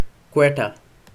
1. ^ /ˈkwɛtə/; Urdu: کوئٹہ, ko'eṭa, [ˈkweːʈə]
Quetta_pronunciation.ogg.mp3